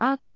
speech
syllable
pronunciation
aak1.wav